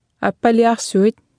Below you can try out the text-to-speech system Martha.
Speech Synthesis Martha